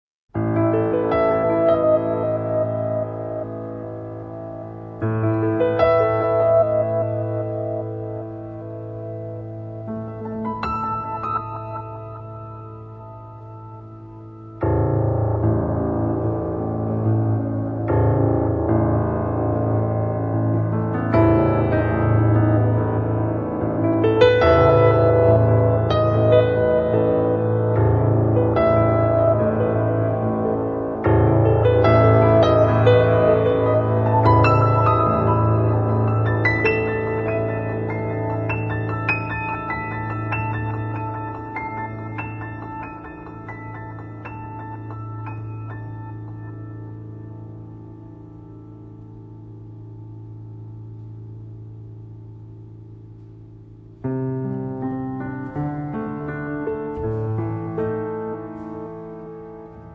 pianoforte
Un album che si avvicina sicuramente più al pop che al jazz.